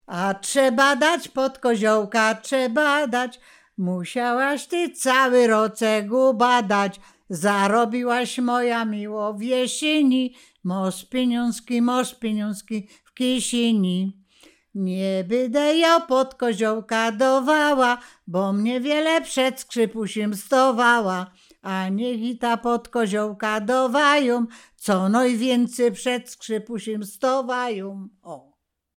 województwo łódzkie, powiat sieradzki, gmina Sieradz, wieś Chojne
Podkoziołek